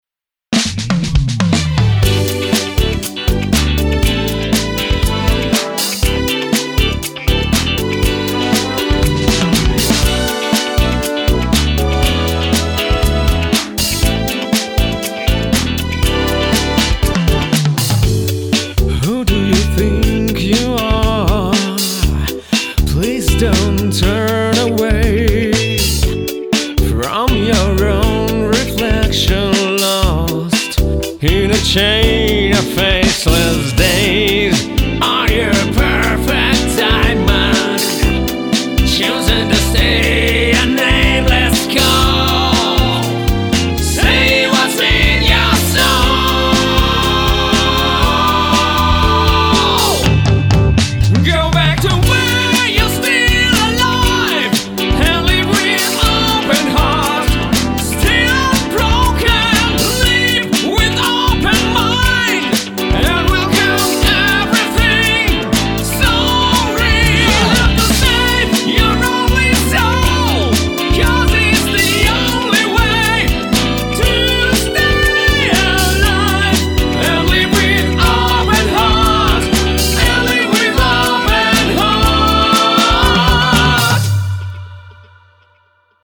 Кардинальная смена стиля...))
Вокал и темп не менялся.
Да, я прекрасно понимаю, что перемудрил с ядовитыми высокими.